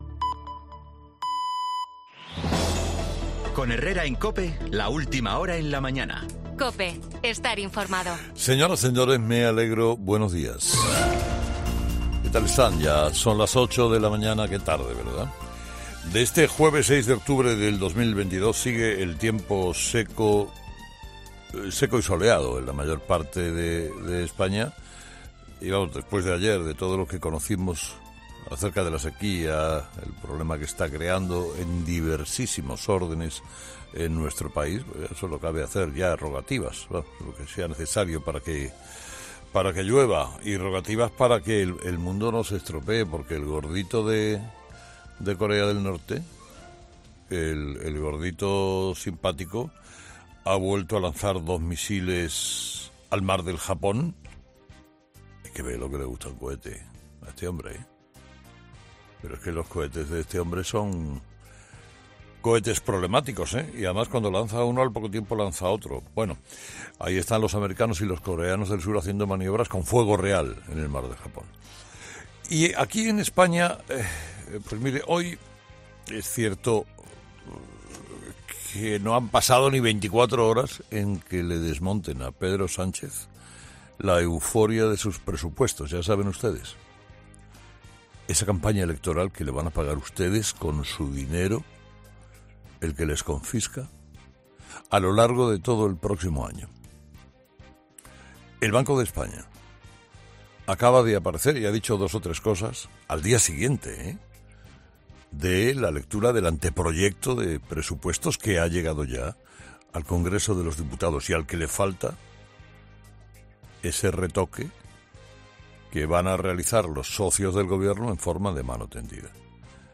Los Presupuestos de Sánchez o la Ley de Memoria Democrática, en el monólogo de Carlos Herrera de este jueves 6 de octubre de 2022